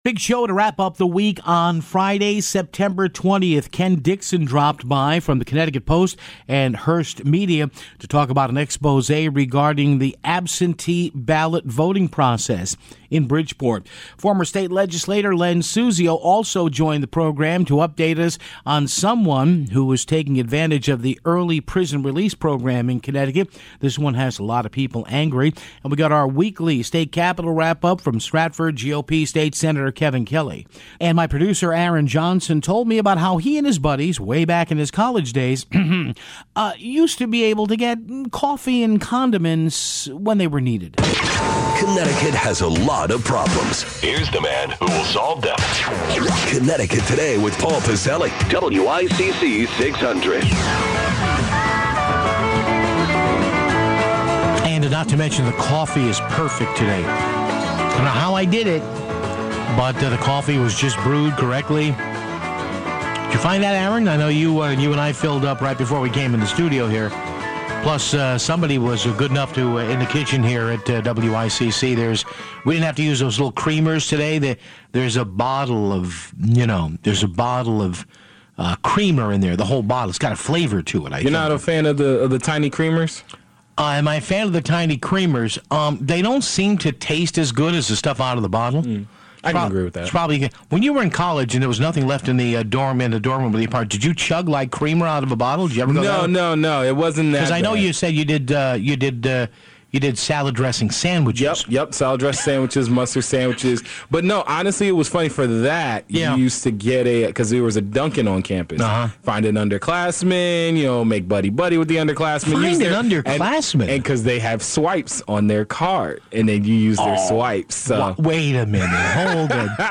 State Sen. Kevin Kelly phones in for his weekly start.